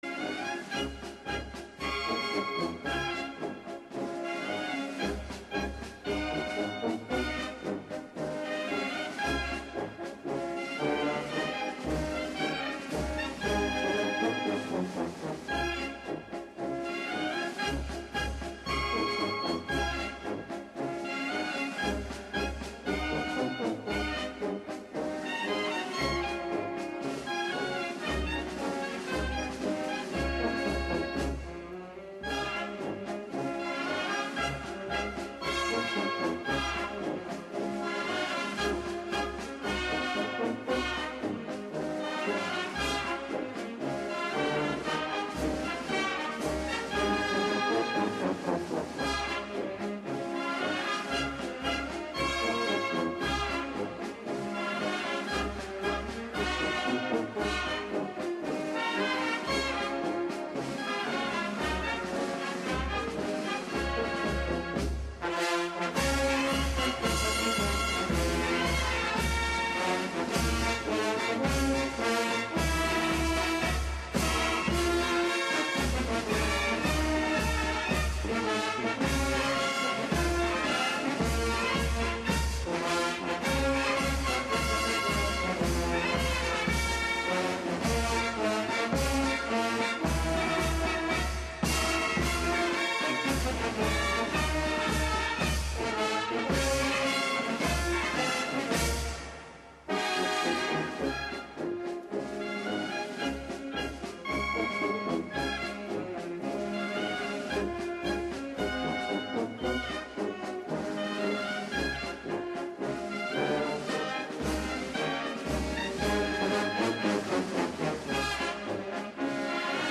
Live from the Thingularity Studios